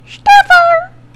Audio / SE / Cries / STUFFUL.mp3